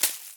leaf_hit.ogg